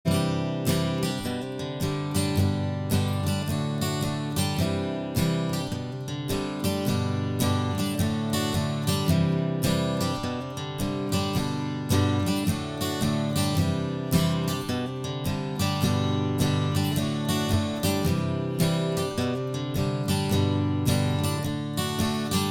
In this audio example, listen to an acoustic guitar: Version A is cardioid directly in front, Version B is bi-directional directly in front, and Version C is bi-directional directly to the side. Notice the change in timbre in Version B, and the dropoff in volume in Version C.
acoustic-bidirectional-offaxis-waveinformer.mp3